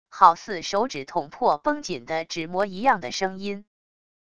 好似手指捅破绷紧的纸膜一样的声音wav音频